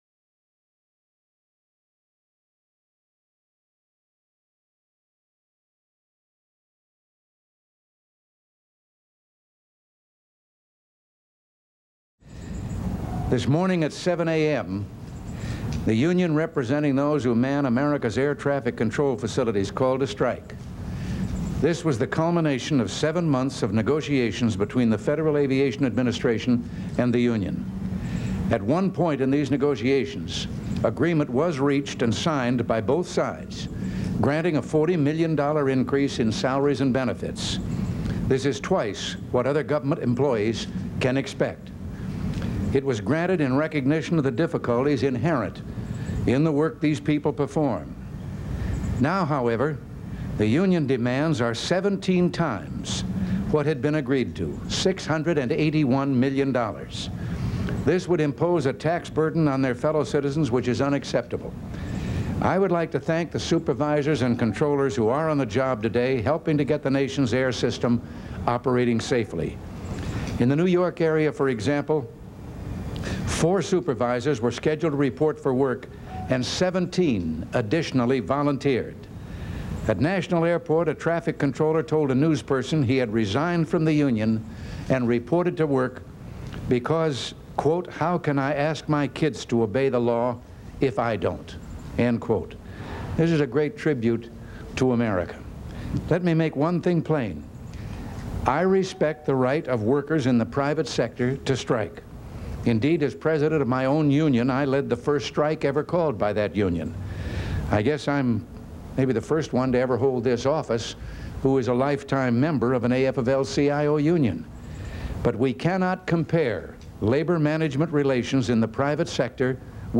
August 3, 1981: Remarks on the Air Traffic Controllers Strike